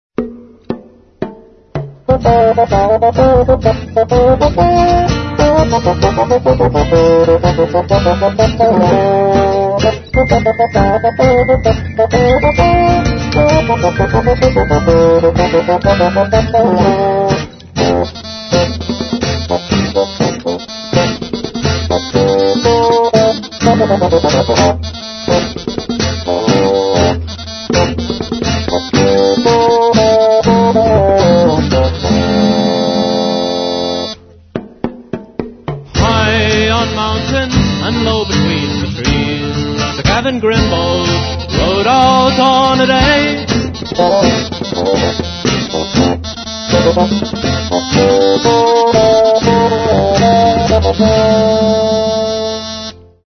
Mono, 0:53, 24 Khz, (file size: 157 Kb).